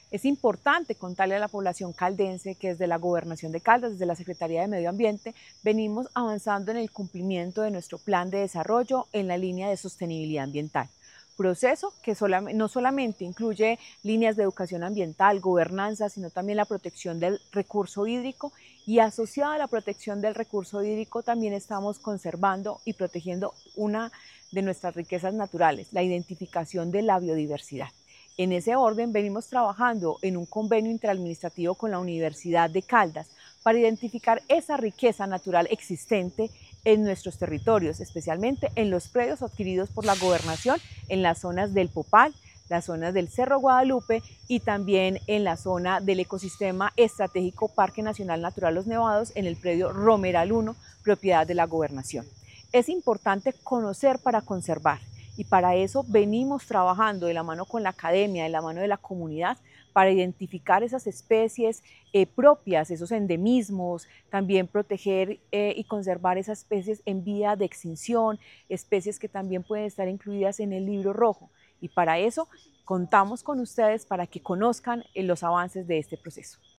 Paola Andrea Loaiza Cruz, secretaria de Medio Ambiente de la Gobernación de Caldas.